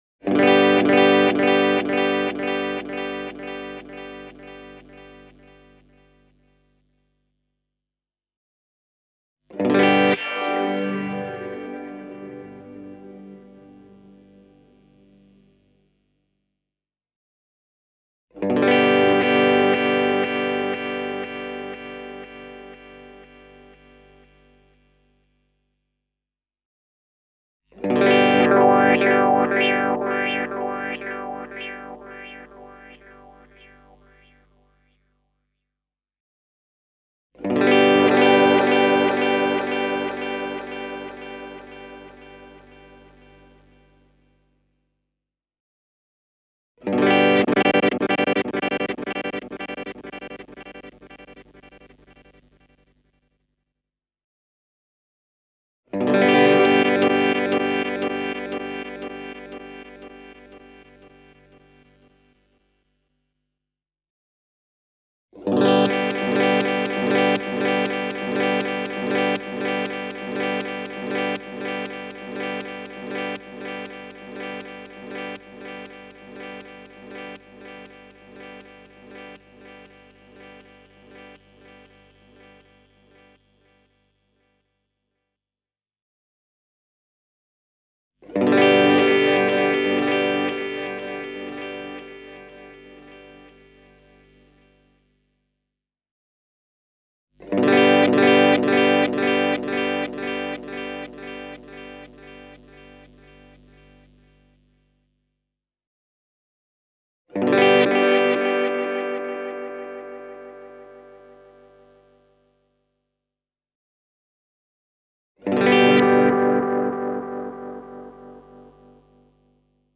Here’s a short clip introducing all twelve Modes, using the same knob settings (with Modulation Depth set to zero). I start with Standard and work my way forward in a clockwise direction: